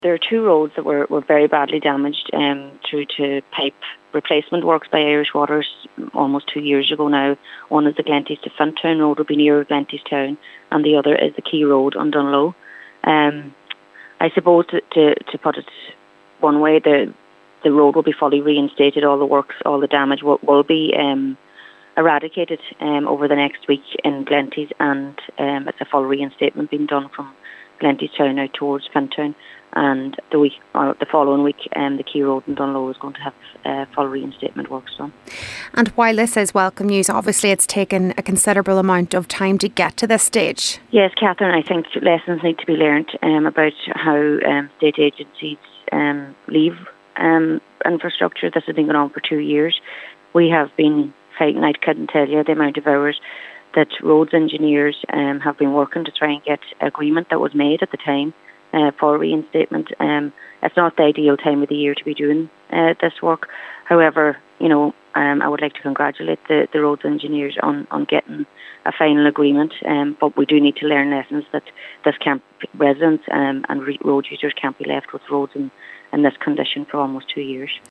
Local Cllr Marie Therese Gallagher says while it’s positive that the issue is finally being addressed, its regrettable that it took so long to get to this stage: